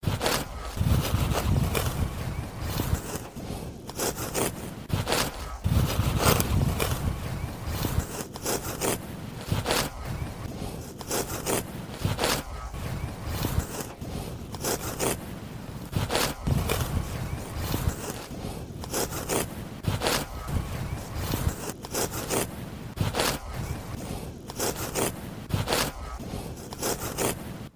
Tiếng Cắt Cỏ bằng tay
Thể loại: Tiếng động
Description: Tiếng cắt cỏ bằng tay sột soạt, xoẹt xoẹt, tiếng liềm cắt cỏ, tiếng dao phát cỏ, tiếng gặt lúa, tiếng lách cách, soạt soạt, tiếng lưỡi dao bén lia. Đây là âm thanh quen thuộc trong lao động nông thôn, thường được tạo ra khi người nông dân dùng liềm hoặc dao để cắt cỏ cho bò ăn, hoặc gặt lúa bằng tay.
tieng-cat-co-bang-tay-www_tiengdong_com.mp3